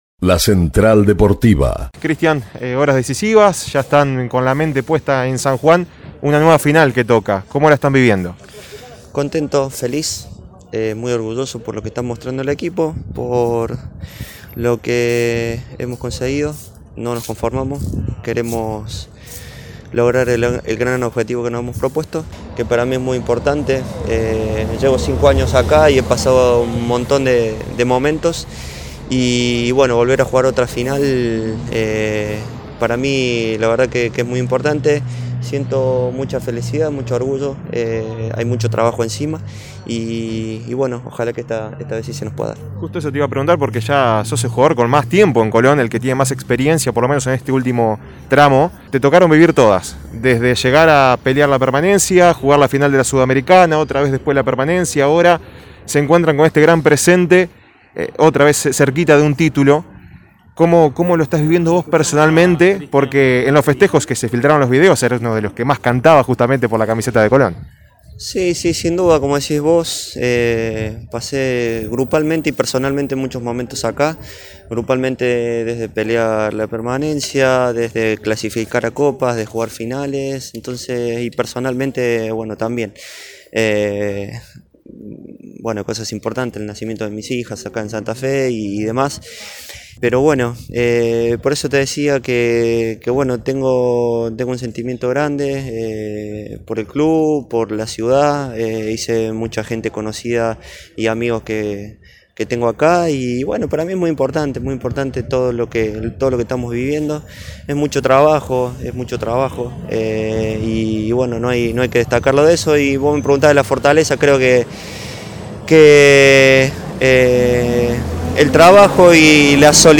Los jugadores de Colón hablaron en La Central Deportiva antes del duelo con Racing. Tras la final de la Sudamericana perdida con Independiente del Valle, vuelven a tener otra chance histórica.